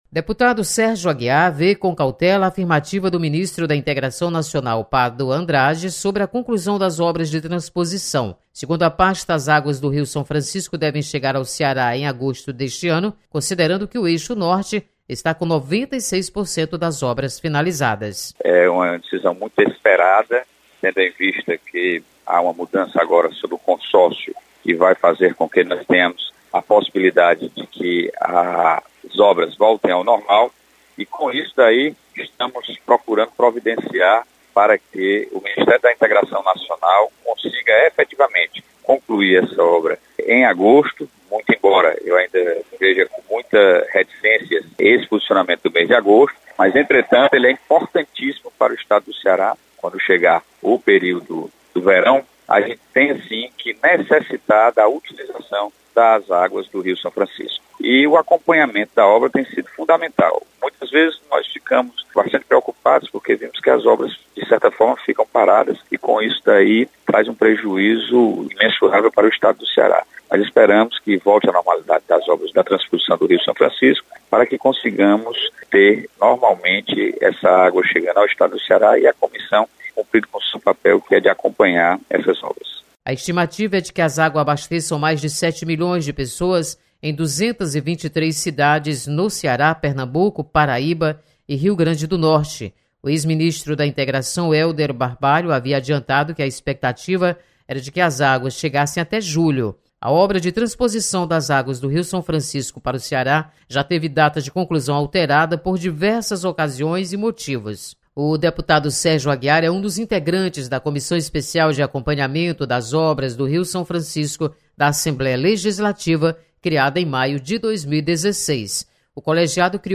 Deputados comentam sobre declaração de ministro da Integração Nacional. Repórter